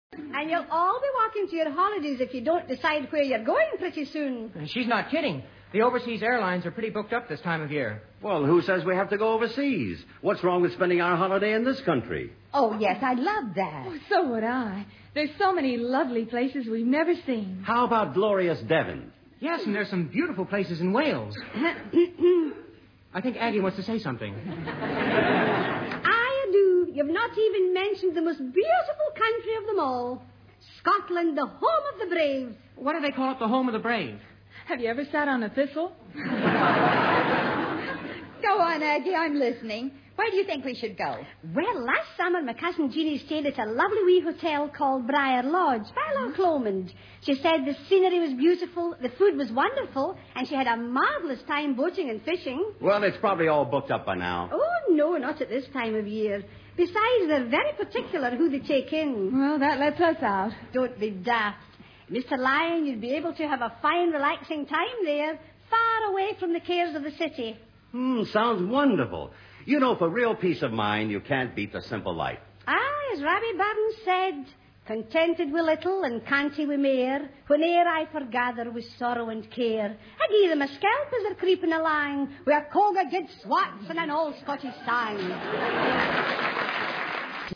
Life with The Lyons was a popular domestic sitcom featuring the real-life family group of Ben Lyon and Bebe Daniels with their children Barbara and Richard.